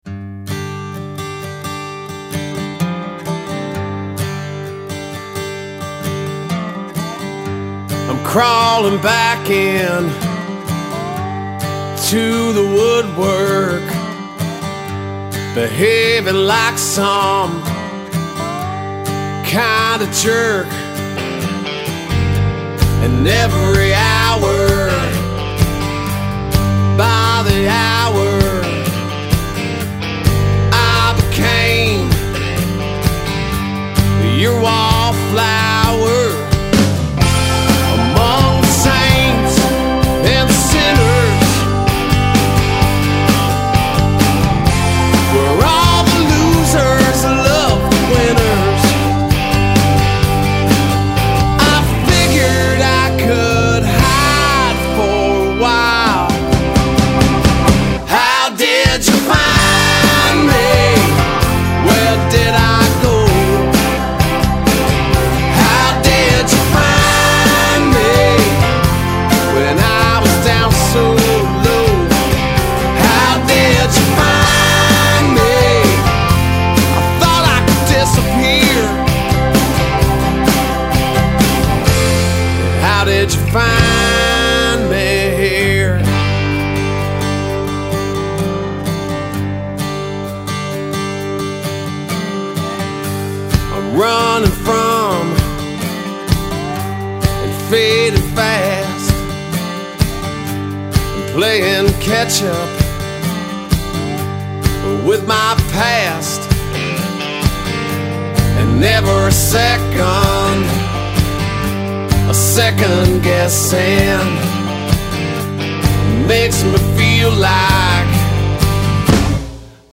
feel-good music
Americana / Alternative Country